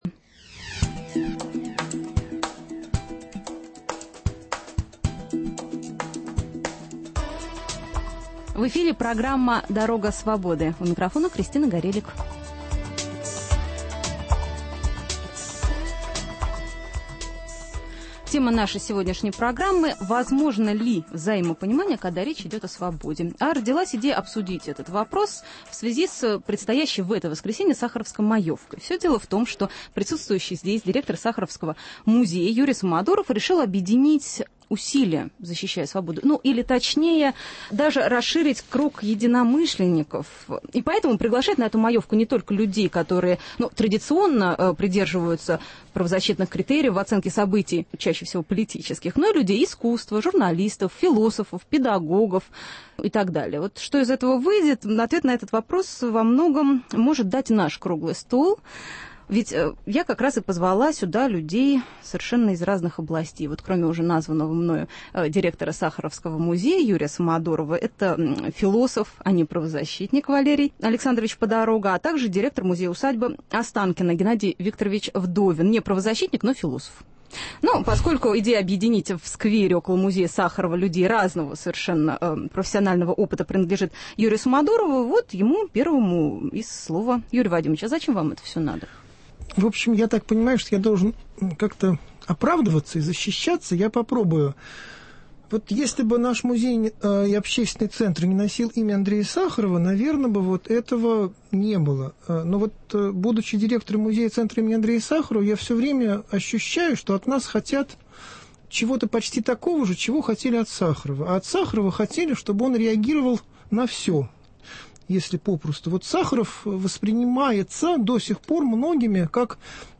Круглый стол: Возможно ли взаимопонимание, когда речь идет о свободе.